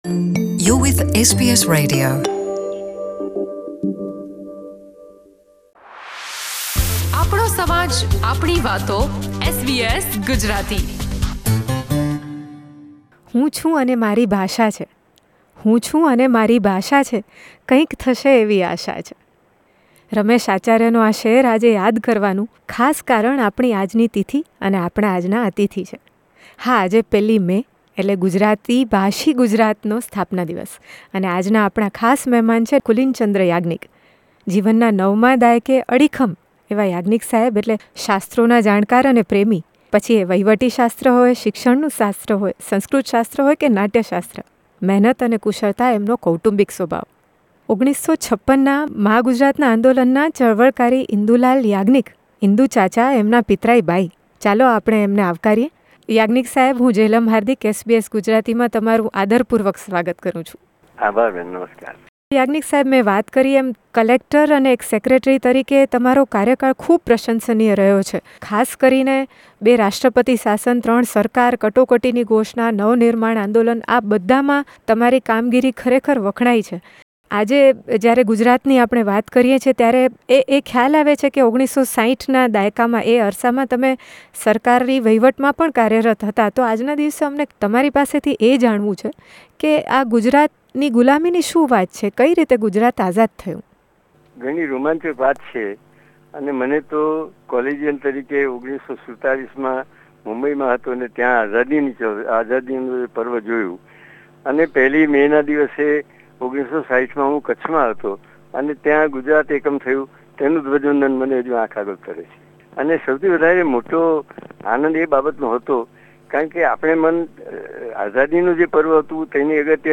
SBS Gujarati સાથેની આ ખાસ વાતચીતમાં